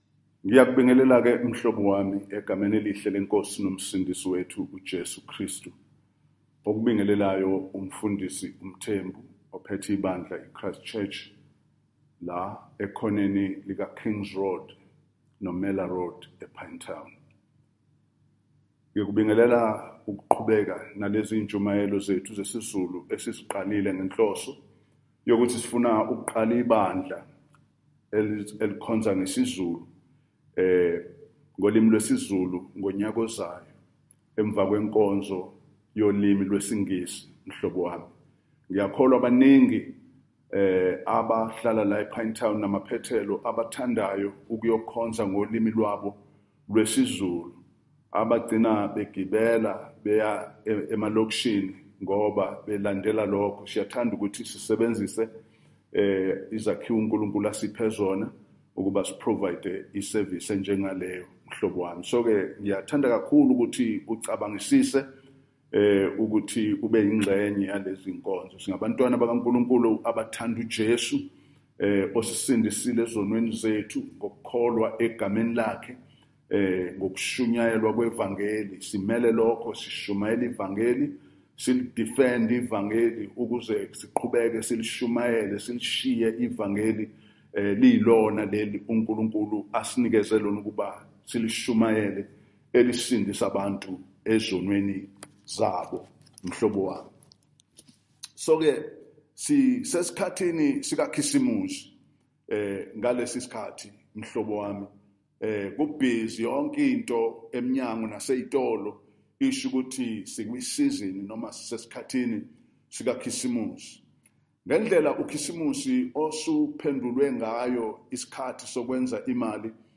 Event: Zulu Sermon